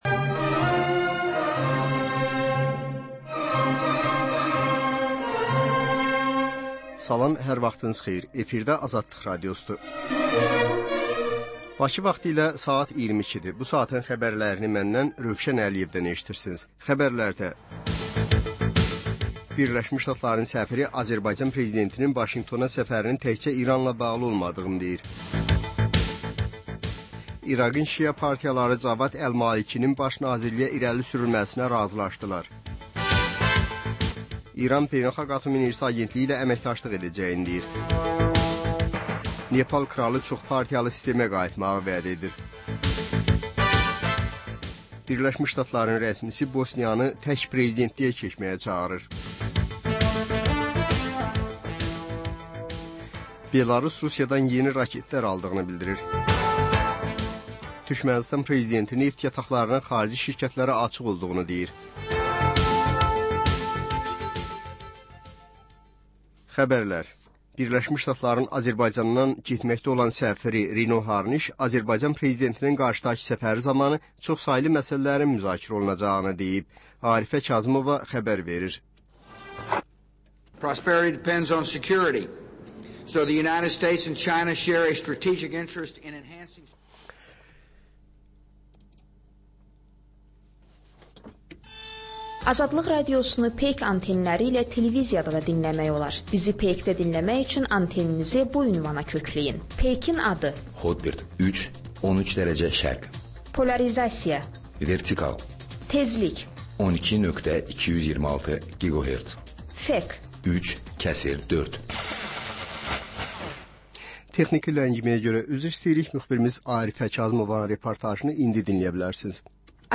Xəbərlər, reportajlar, müsahibələr. Və sonda: Qlobus: Xaricdə yaşayan azərbaycanlılar barədə xüsusi veriliş.